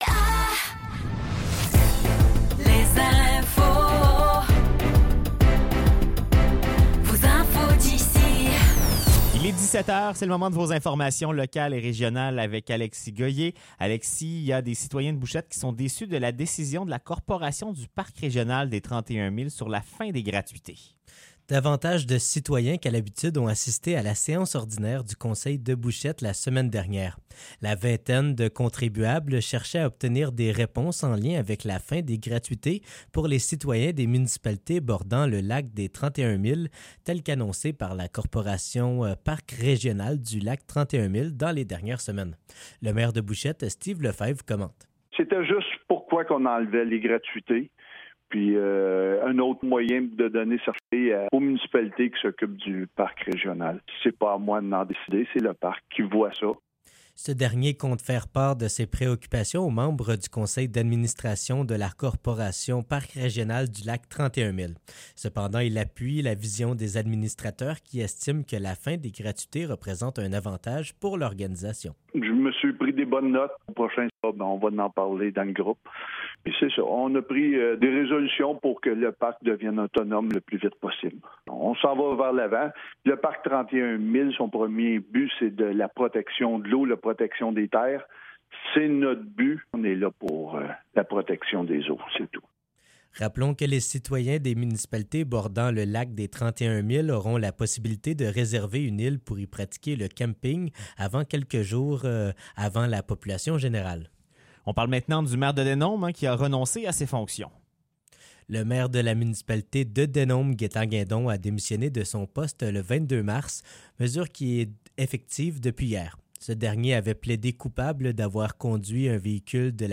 Nouvelles locales - 25 mars 2024 - 17 h